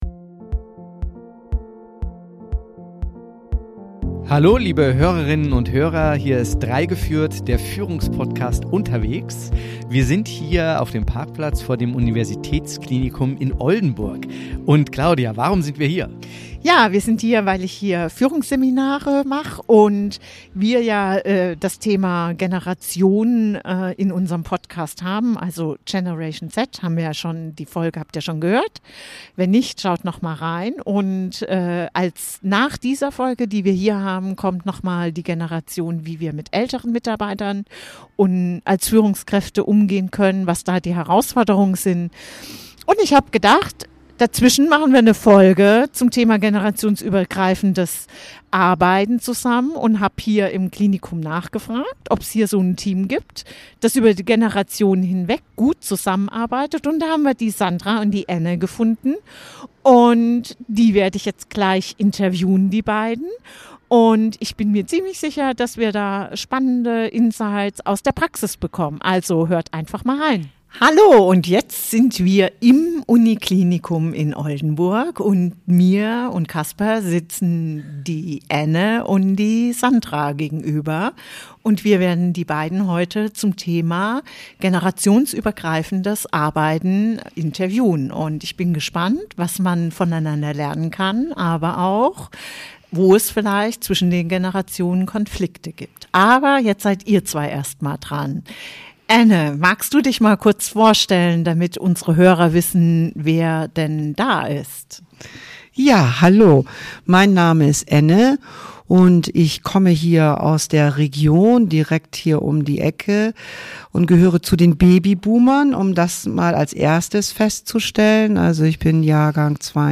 In dieser Podcastfolge spreche ich mit zwei Mitarbeitenden aus der Pädiatrie des Klinikums Oldenburg, die aus ihrem Arbeitsalltag berichten. Gemeinsam beleuchten wir, wie sich Ausbildungen im Gesundheitswesen im Laufe der Zeit verändert haben, wie unterschiedliche Rollen definiert werden und warum generationsübergreifendes Arbeiten für die Qualität der Zusammenarbeit so entscheidend ist.